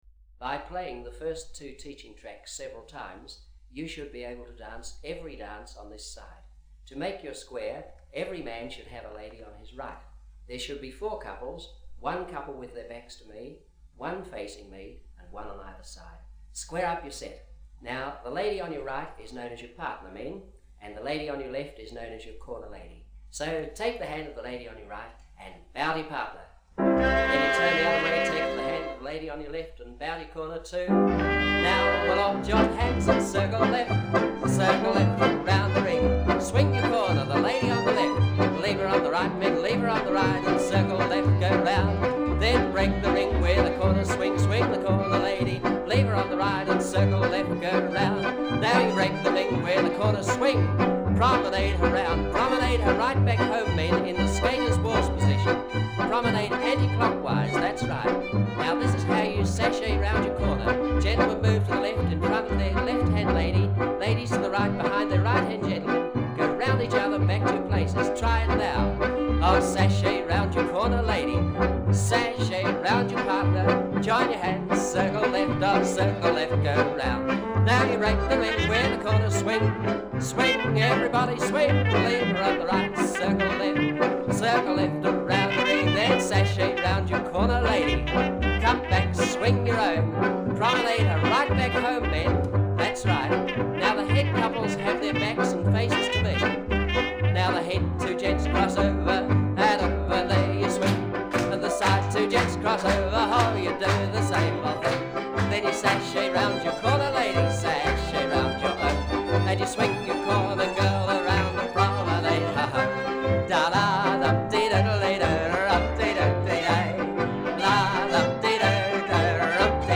#1 Teaching Track -